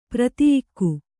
♪ pfrtiyiḍu